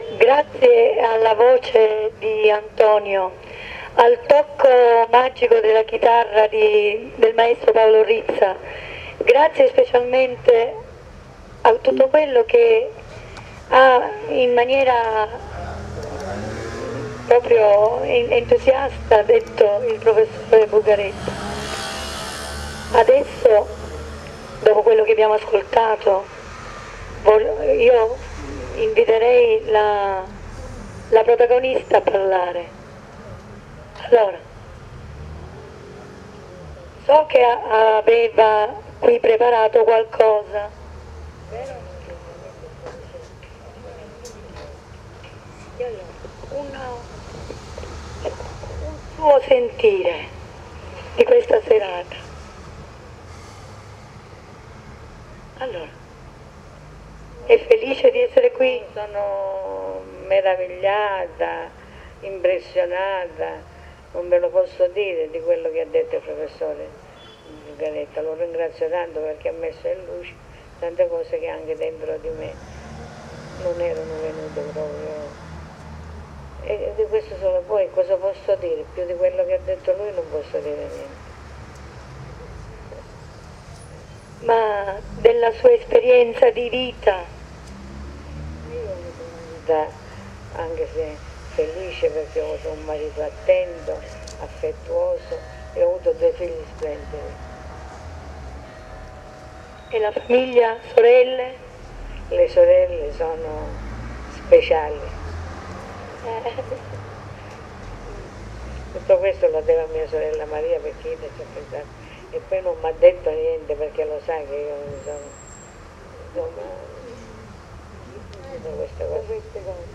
INTERVISTA
durante la presentazione del volume di poesia Terra mia